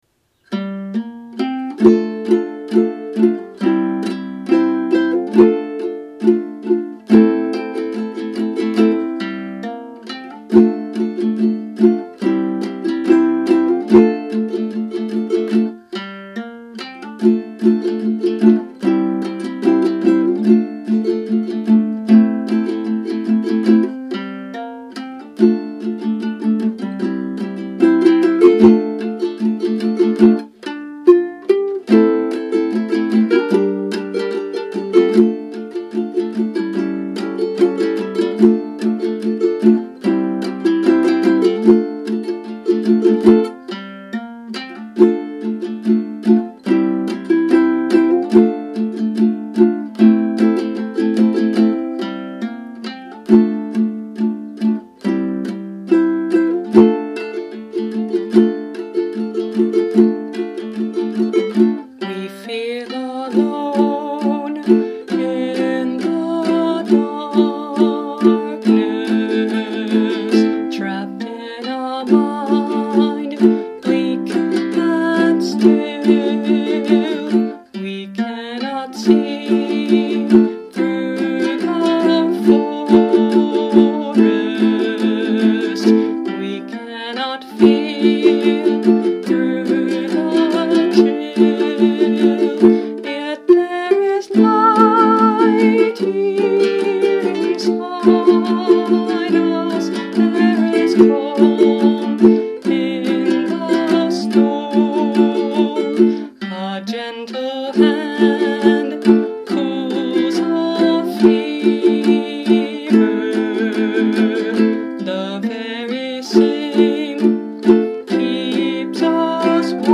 Mahogany Concert Ukulele